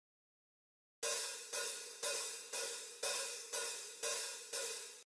貝のように2枚合わせた構造になっていて、足で開閉の操作ができて、演奏中に開閉して音を変えられるのが特徴です。
ハイハットオープン
hhopen.mp3